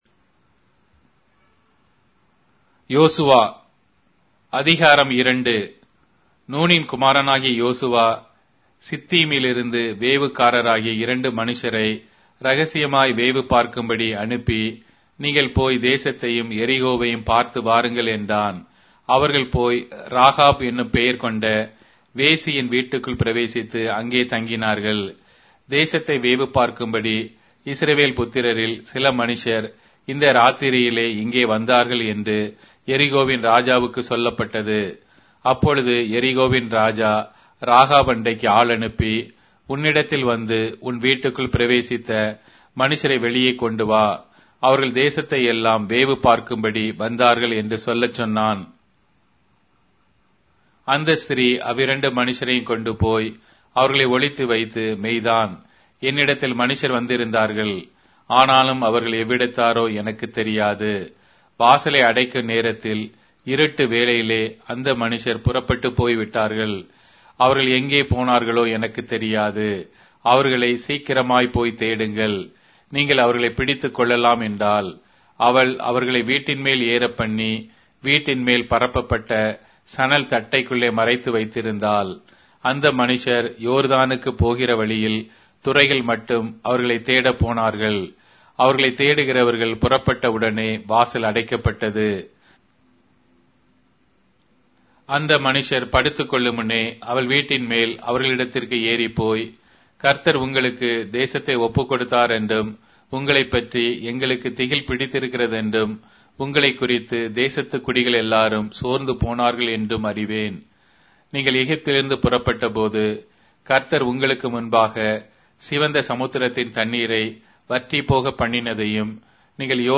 Tamil Audio Bible - Joshua 7 in Irvur bible version